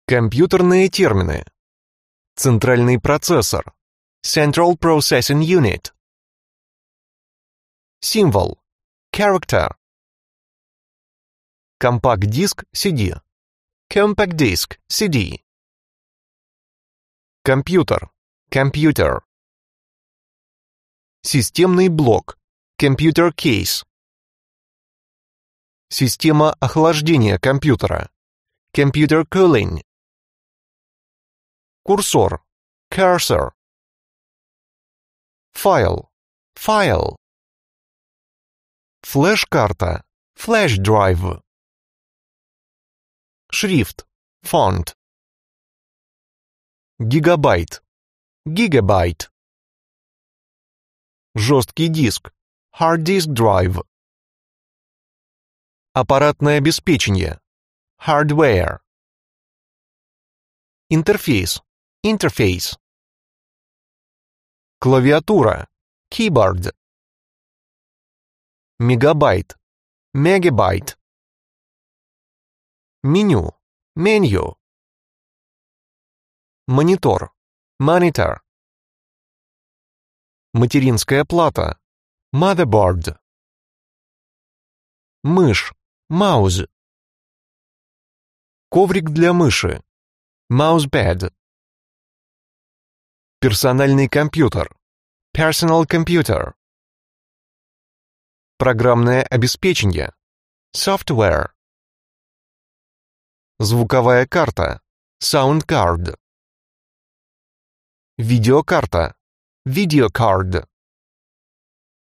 Аудиокнига Английский язык. Большой словарь. 4000 слов | Библиотека аудиокниг